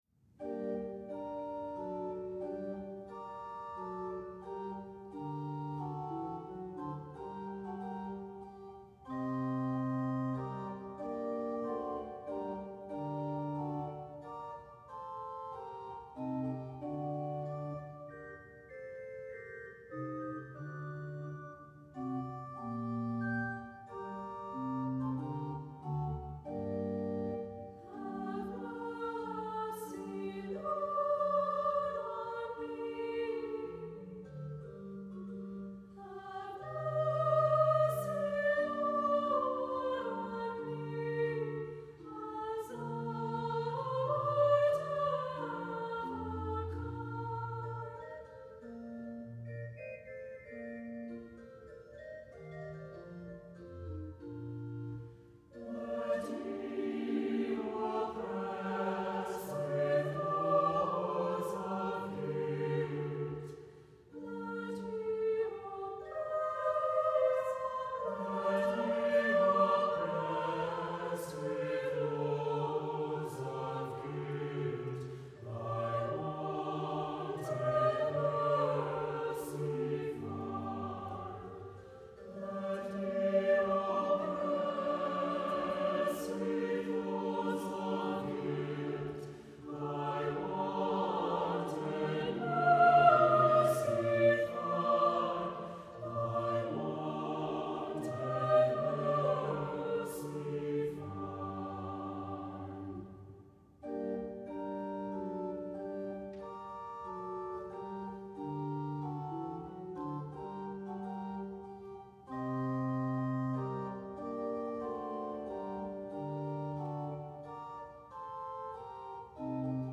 Voicing: SATB and Solo